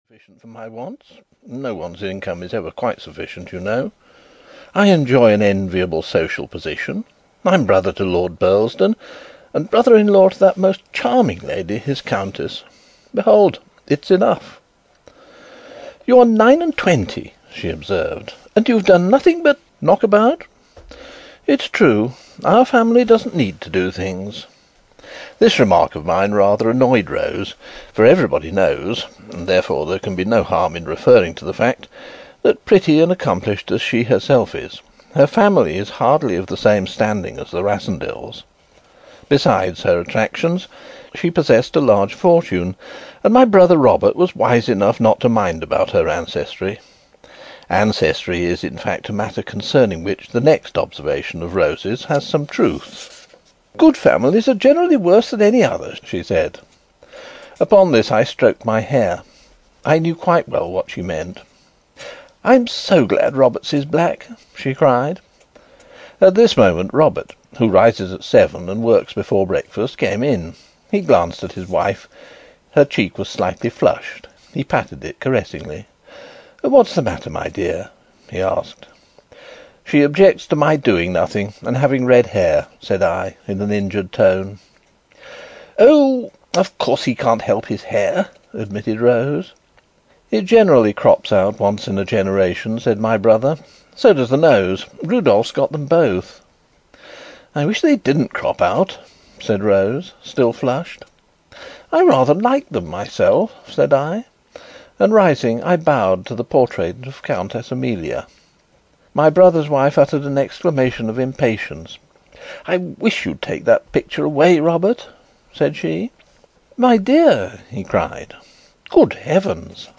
The Prisoner of Zenda (EN) audiokniha
Ukázka z knihy